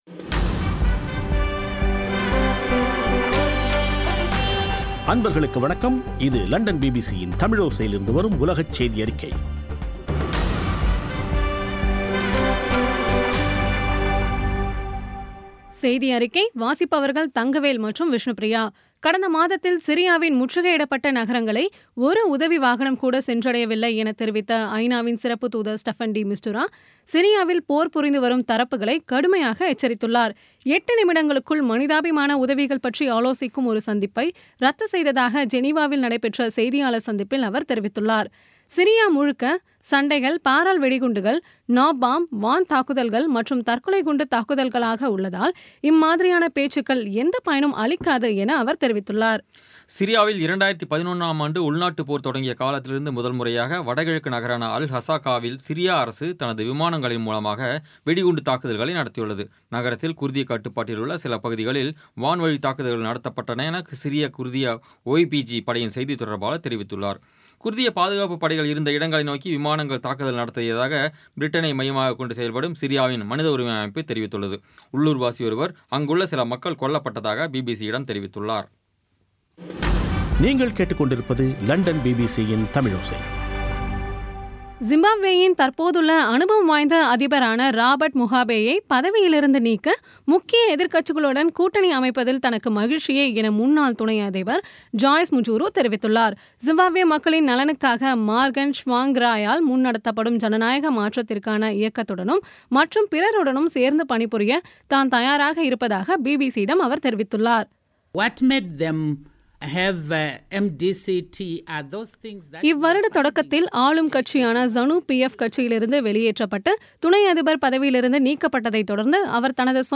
பி பி சி தமிழோசை செய்தியறிக்கை (18/08/2016)